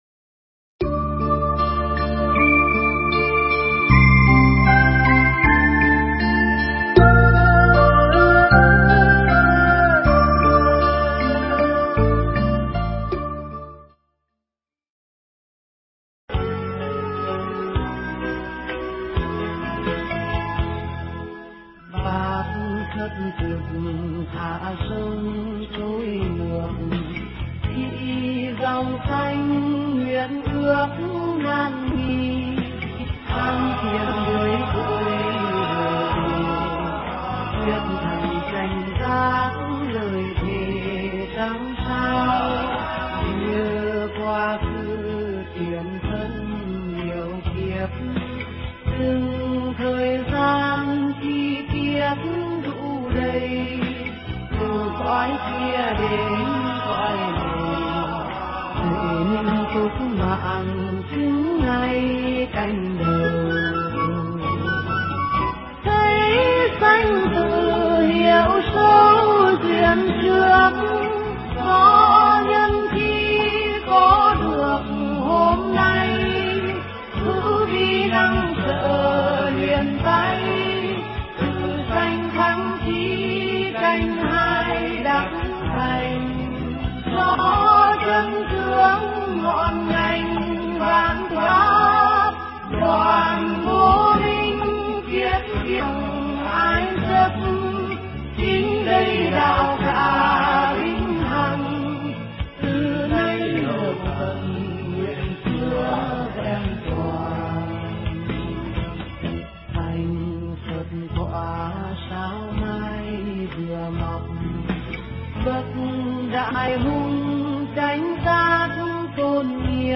Mp3 pháp thoại Đừng Mong Muốn Thay Đổi Bản Tánh Người Khác